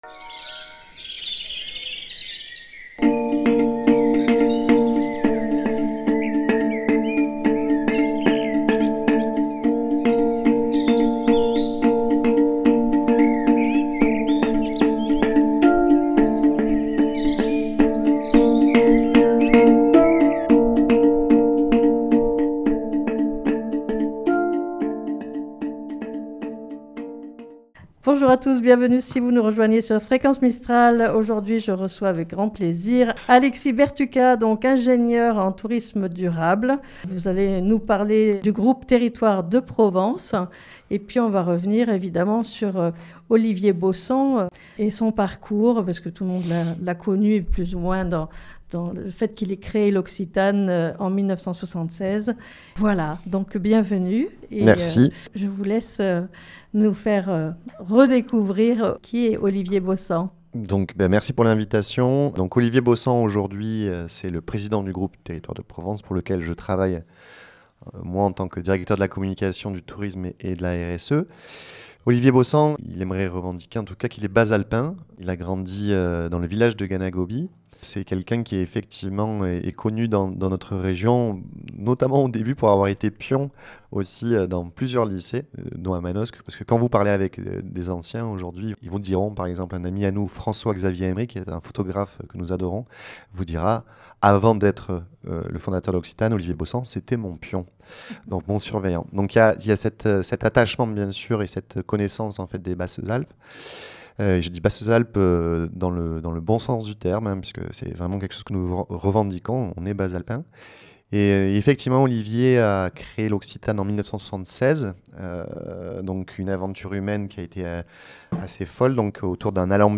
studio de radio de Fréquence mistral